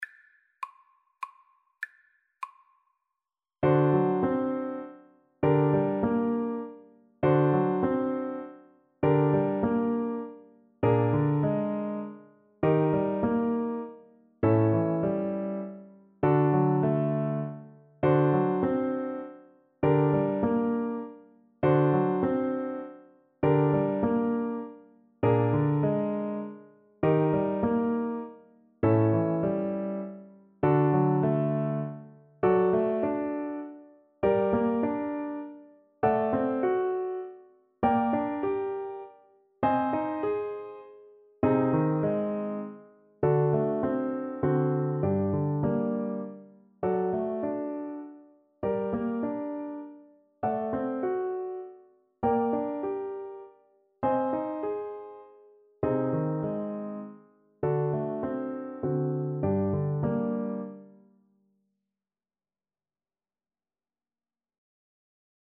Play (or use space bar on your keyboard) Pause Music Playalong - Piano Accompaniment Playalong Band Accompaniment not yet available reset tempo print settings full screen
G minor (Sounding Pitch) D minor (French Horn in F) (View more G minor Music for French Horn )
3/4 (View more 3/4 Music)
Etwas bewegt
Classical (View more Classical French Horn Music)